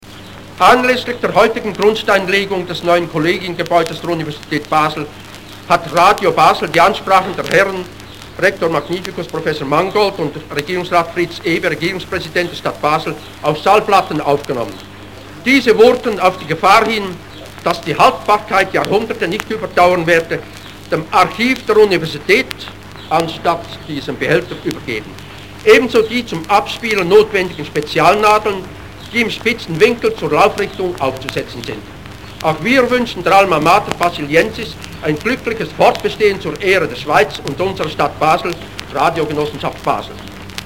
Radiobericht zur Universitätsgründung. Sammlung der Basler Radiogesellschaft «Documenta Basiliensia acustica»